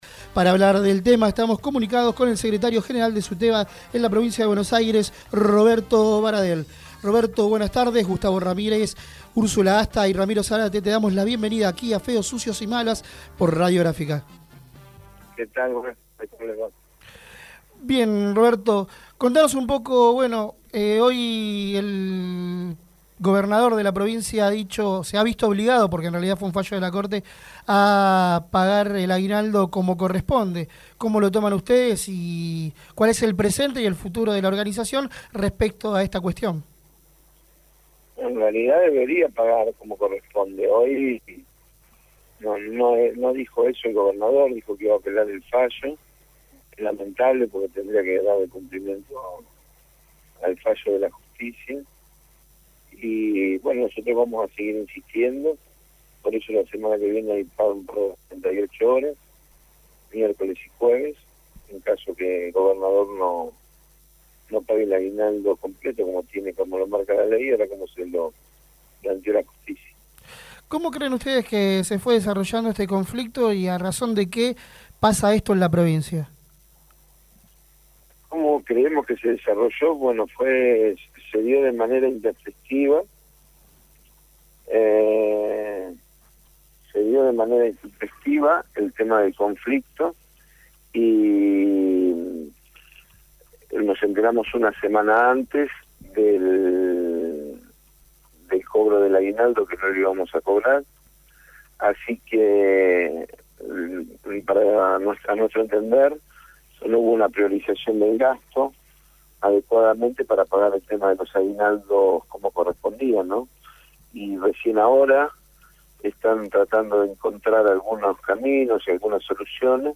Roberto Baradel, Secretario General de Suteba, habló en Feos, Sucios y Malas (Sábados de 18 a 20hs).